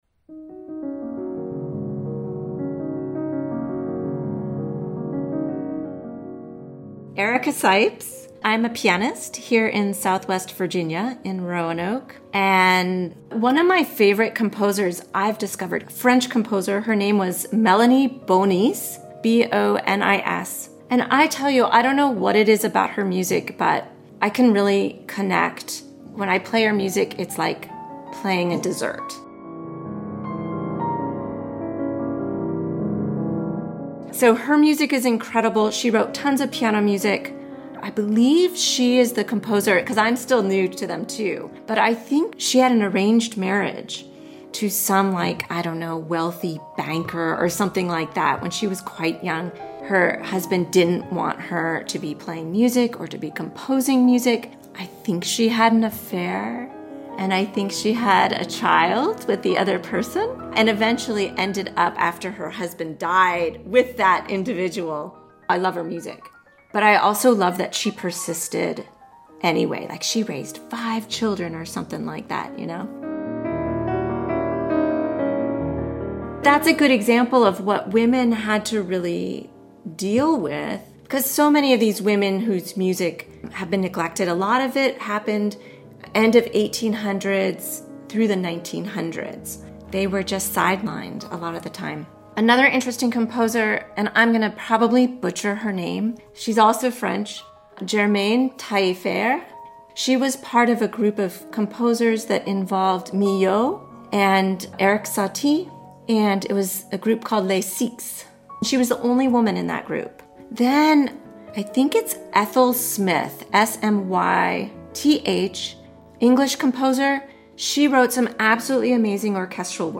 Classical Interviews